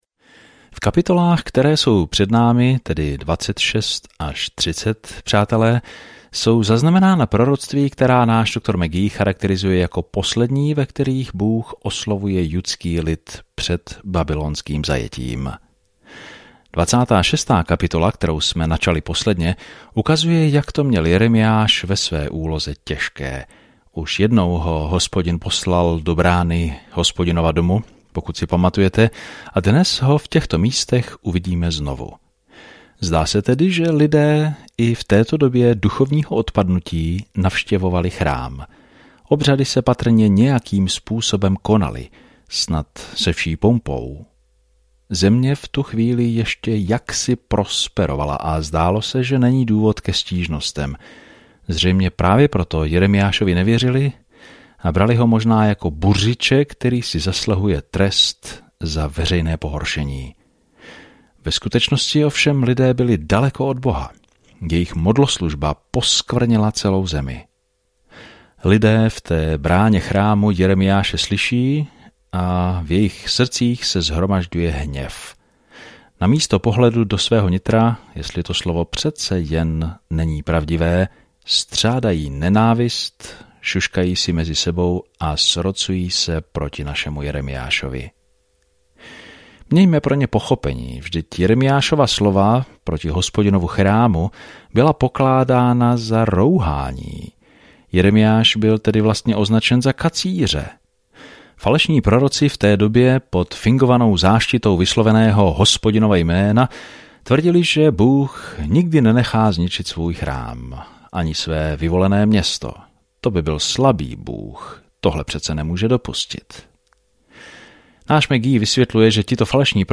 Písmo Jeremiáš 26:16-24 Jeremiáš 27 Jeremiáš 28 Den 17 Začít tento plán Den 19 O tomto plánu Bůh si vybral Jeremiáše, muže něžného srdce, aby předal drsné poselství, ale lidé toto poselství nepřijímají dobře. Denně procházejte Jeremiášem, poslouchejte audiostudii a čtěte vybrané verše z Božího slova.